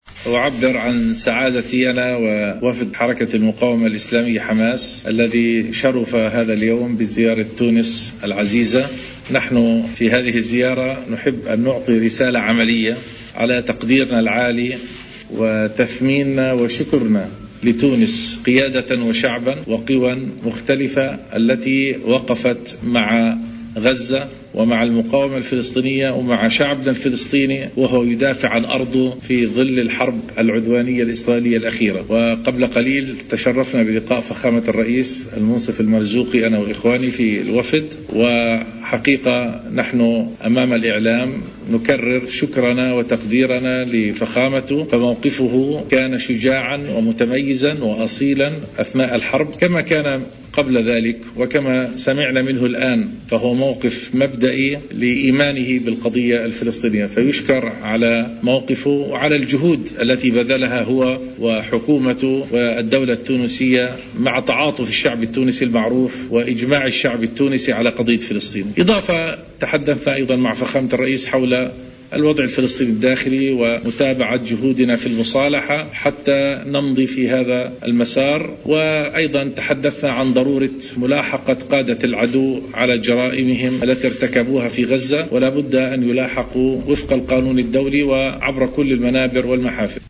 التقى اليوم الجمعة رئيس الجمهورية المؤقت محمد المنصف المرزوقي برئيس المكتب السياسي لحركة المقاومة الفلسطينية حماس خالد مشعل الذي تحدث عقب اللقاء إلى الإعلاميين عن فحوى زيارته إلى تونس.